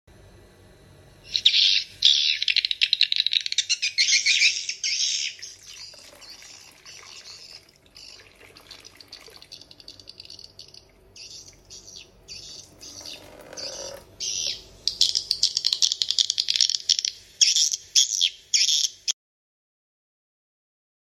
Audax Horn Tweeter AX 5 [For sound effects free download